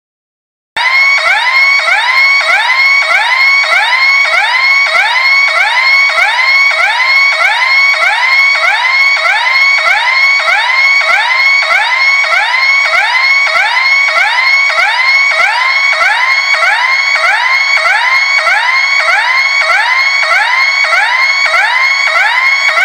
Sonido de EVACUACIÓN en Quintiliano (mp3)
sirena_quintiliano.mp3